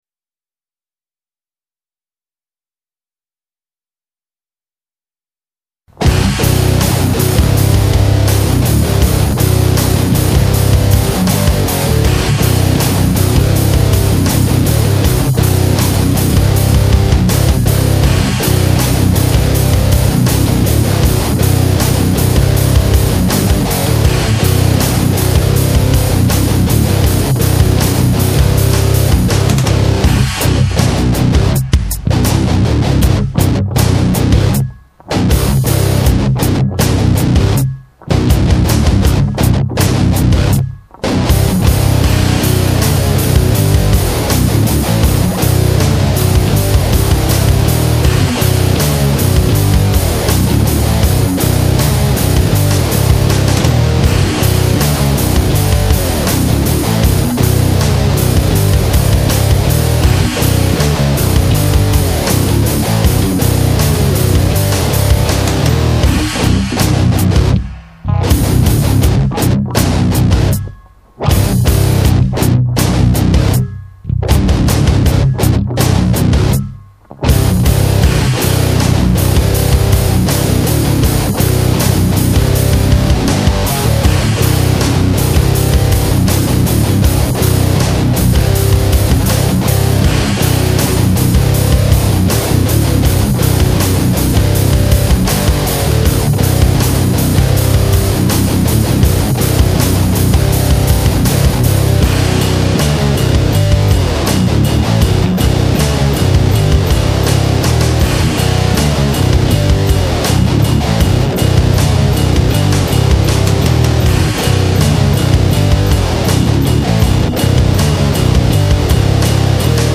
wow that rocked